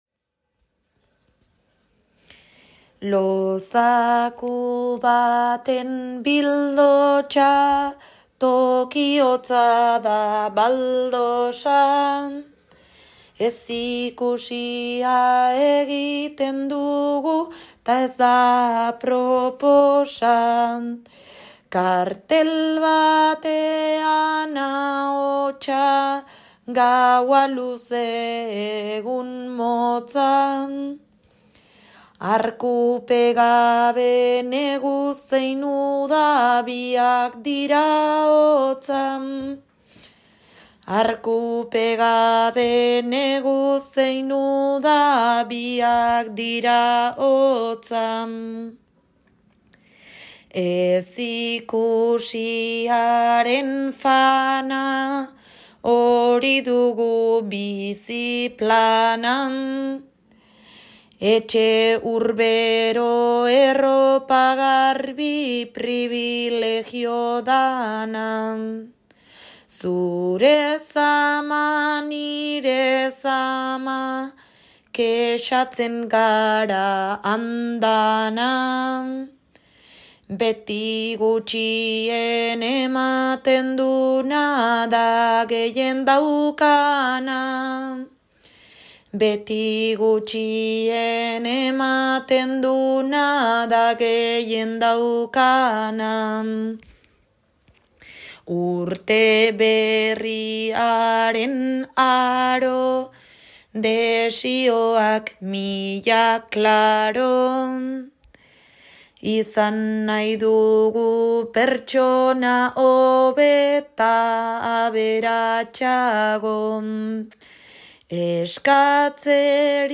bertso sorta